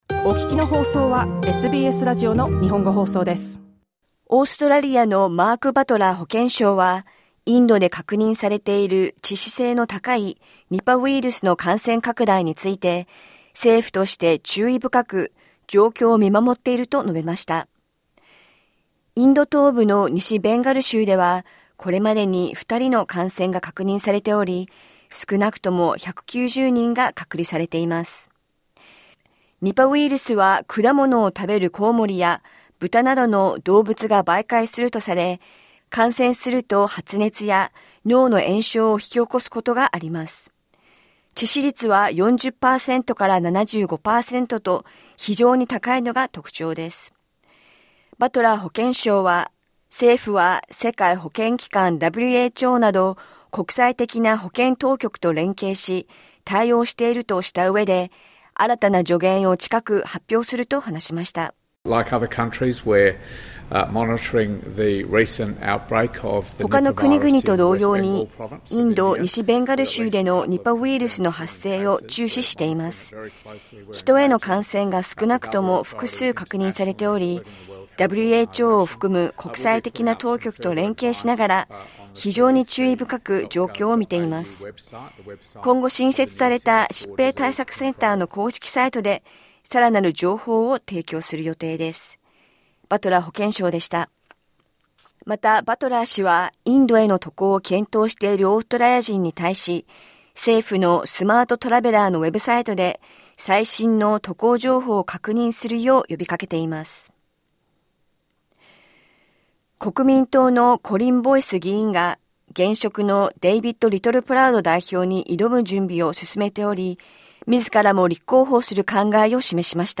SBS日本語放送週間ニュースラップ 1月31日土曜日